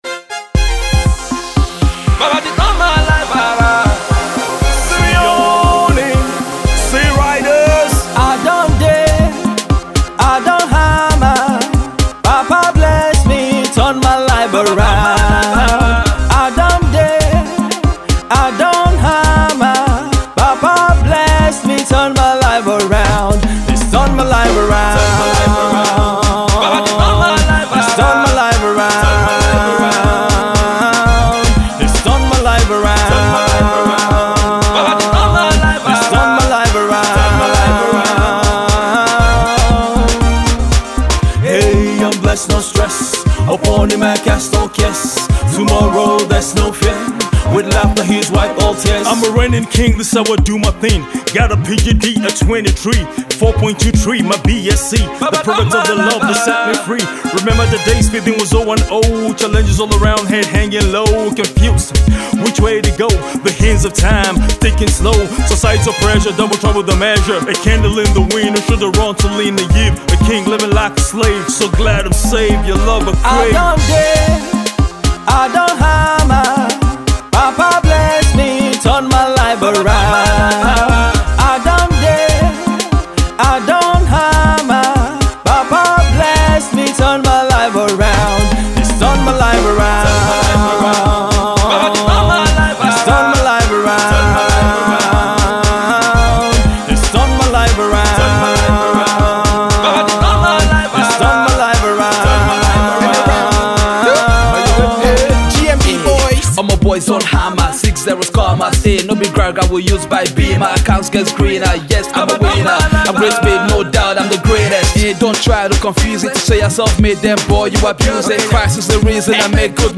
Gospel
dynamic rap artist and an embodiment of talent